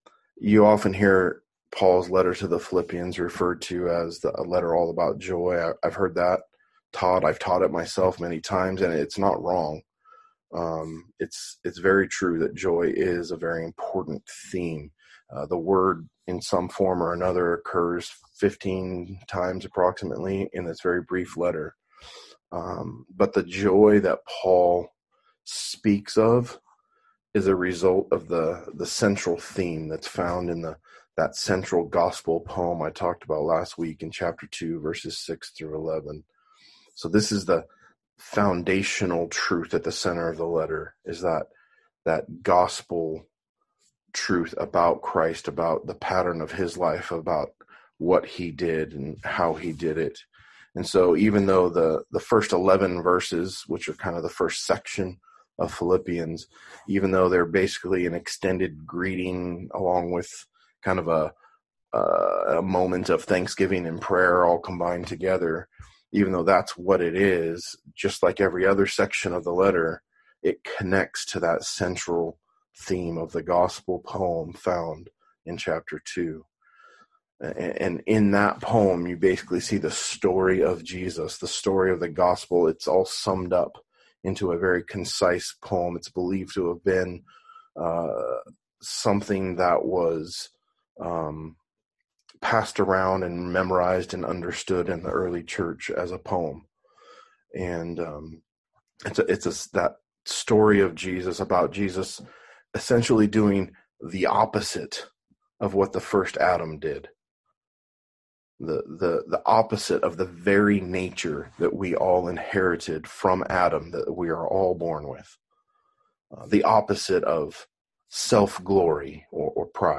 A message from the series "Philippians."